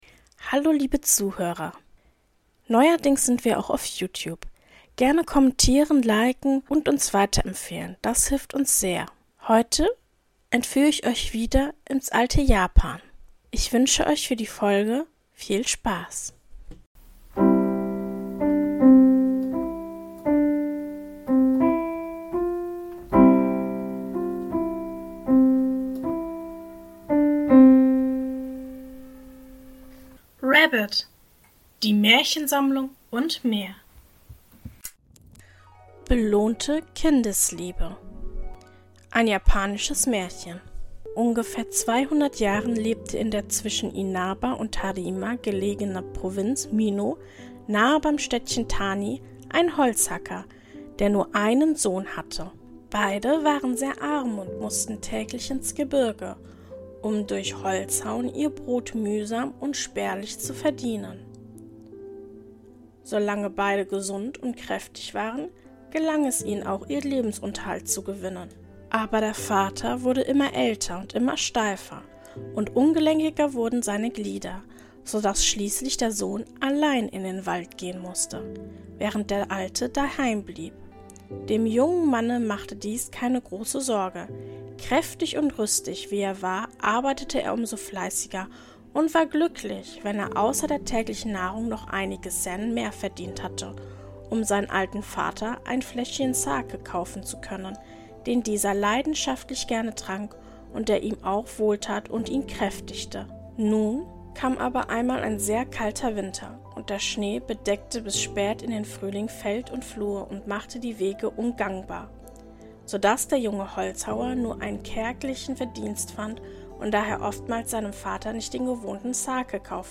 In der heutigen Folge lese ich Folgendes vor: 1. Belohnte Kindesliebe. 2.